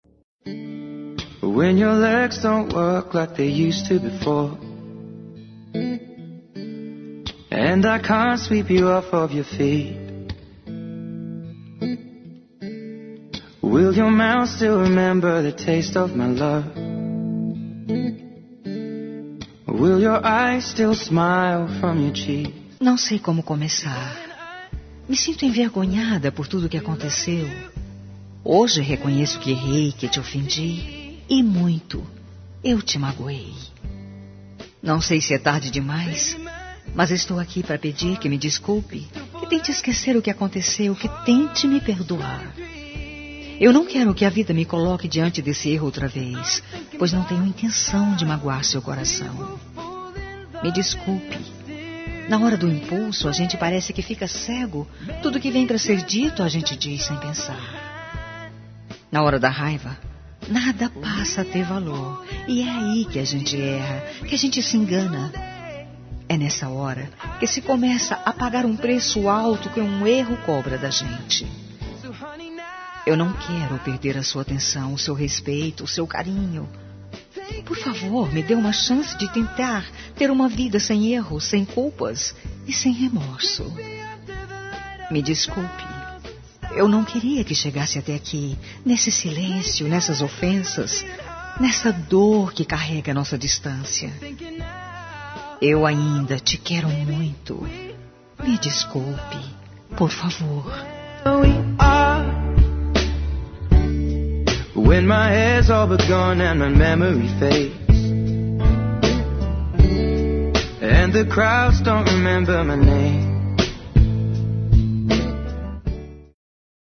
Telemensagem Desculpa Romântica – Voz Feminina – Cód: 8127 – Falei Sem Pensar